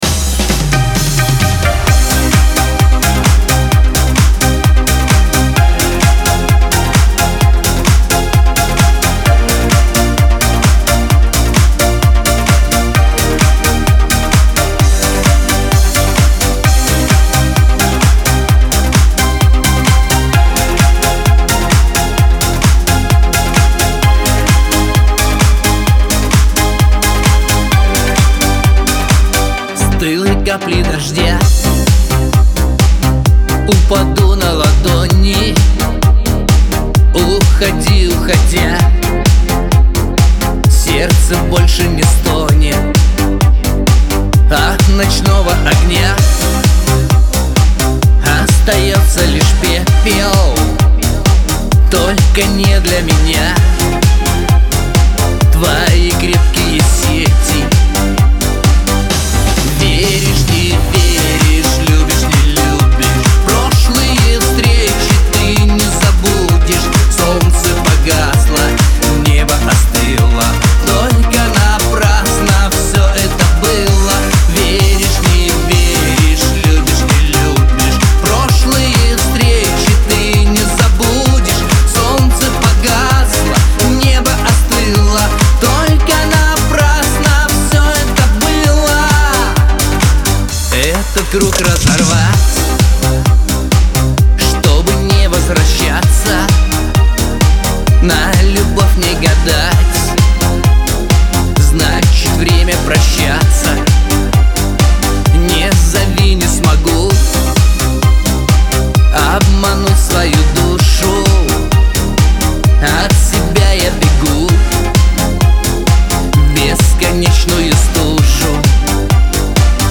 pop
диско